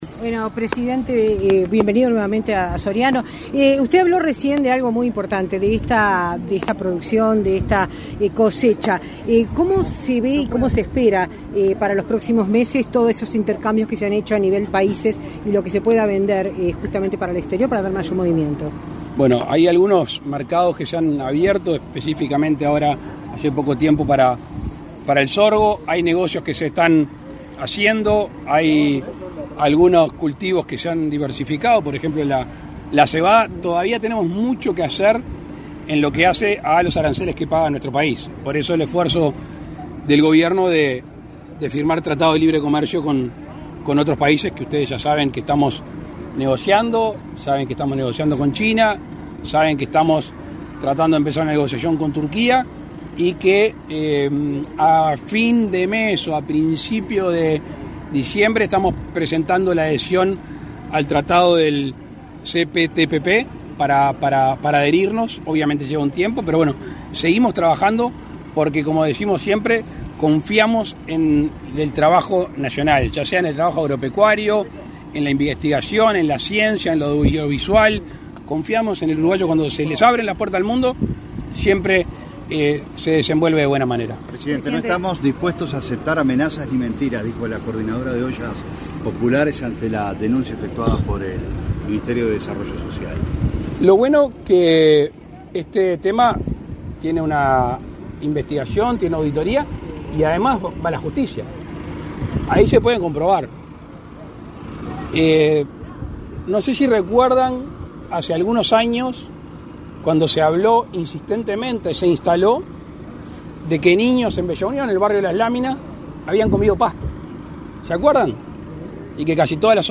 Declaraciones a la prensa del presidente de la República, Luis Lacalle Pou, en Dolores
Declaraciones a la prensa del presidente de la República, Luis Lacalle Pou, en Dolores 15/11/2022 Compartir Facebook X Copiar enlace WhatsApp LinkedIn Tras participar en la inauguración de la cosecha de trigo, en la ciudad de Dolores, este 15 de noviembre, el presidente de la República realizó declaraciones a la prensa.